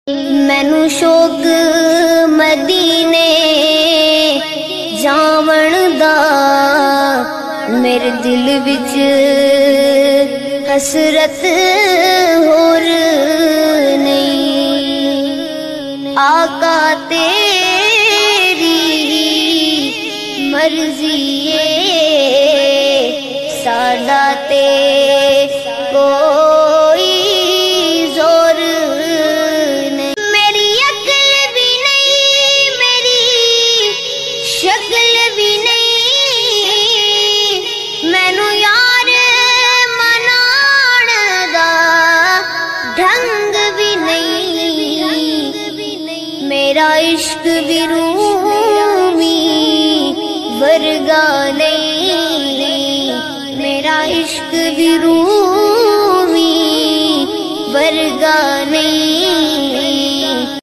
Naat Sharif